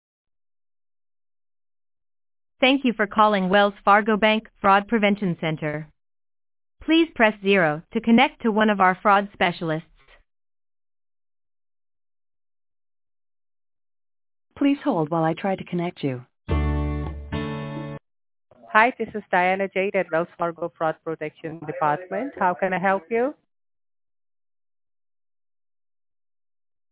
Scams Robo Calls